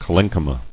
[col·len·chy·ma]